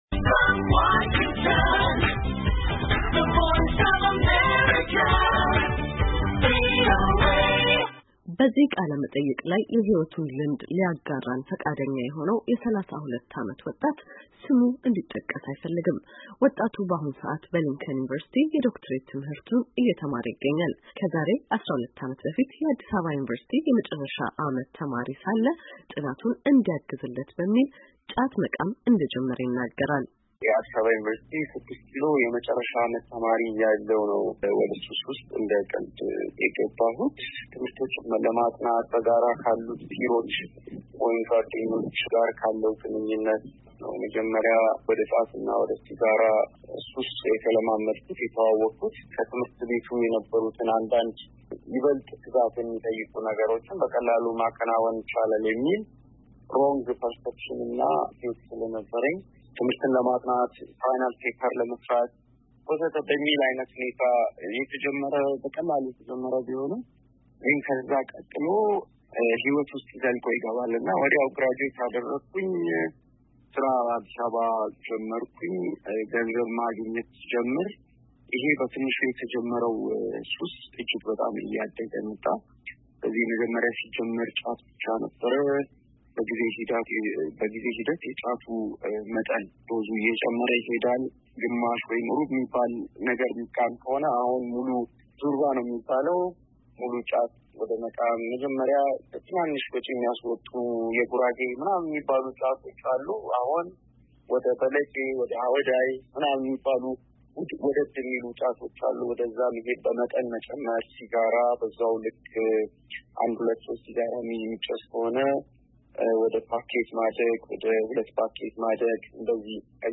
ሁለት ከዚህ ቀደም በሱስ ሕይወት ውስጥ የነበሩና በሱስ የተነሳ ሕይወታቸውን እስከማጥፋት ከደረሱ በኋላ የወጡ ወጣቶችን አነጋግራ ተከታዩን አሰናድታለች፡፡